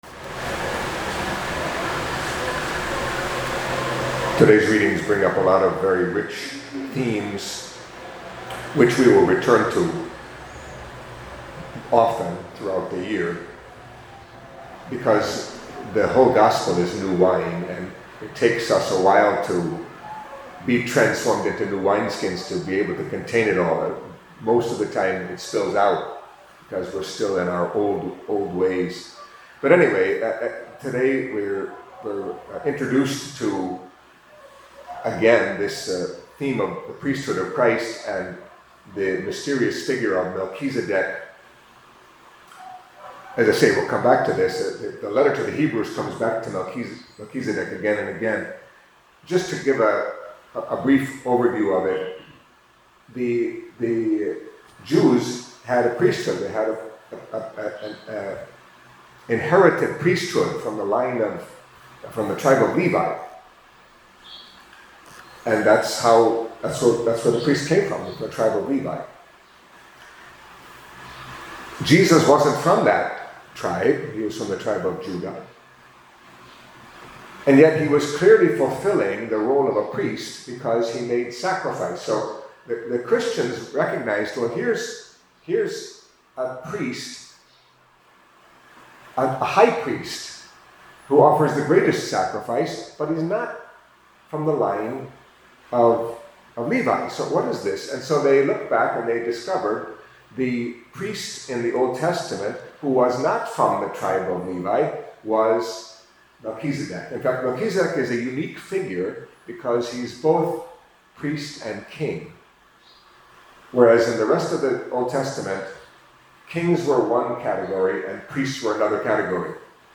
Catholic Mass homily for Monday of the Second Week in Ordinary Time